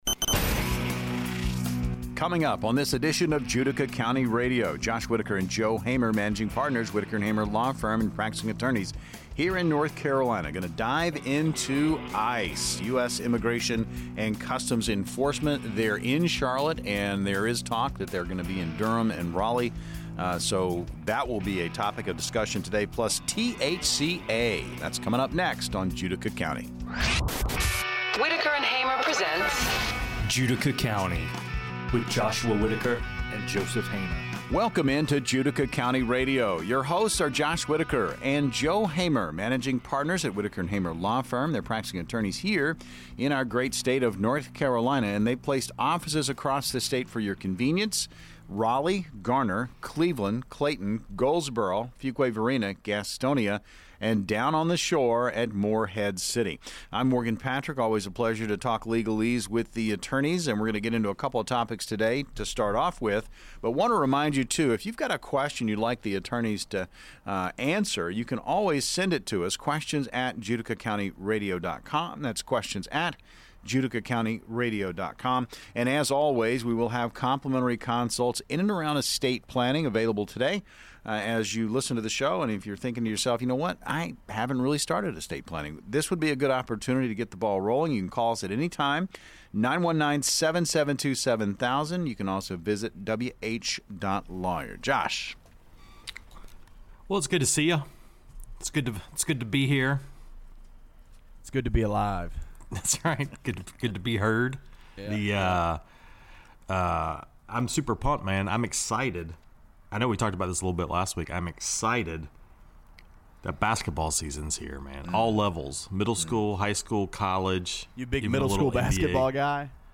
They emphasize the legal rights of individuals regarding immigration enforcement and the necessity of having a solid estate plan in place to protect loved ones. The conversation is both informative and engaging, providing listeners with valuable insights into legal matters affecting their lives.